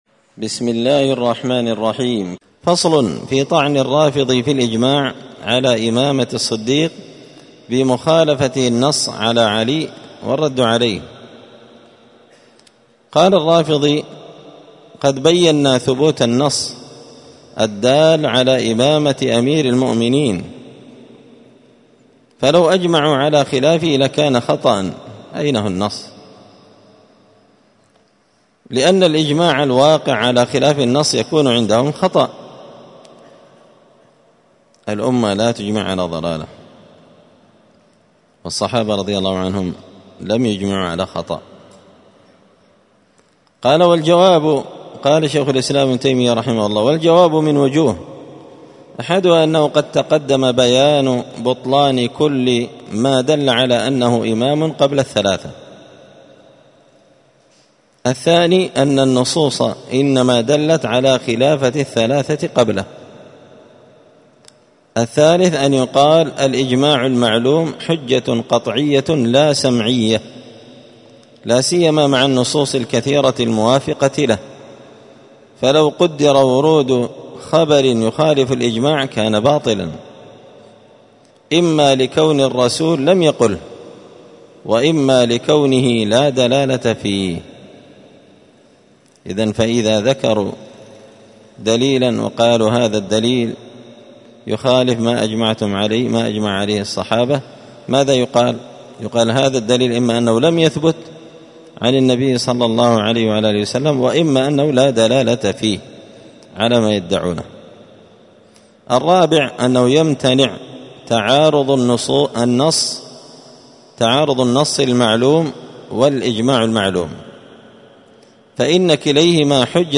*الدرس الأربعون بعد المائتين (240) فصل في طعن الرافضي في الإجماع على إمامة الصديق بمخالفته النص على علي والرد عليه*
مسجد الفرقان قشن_المهرة_اليمن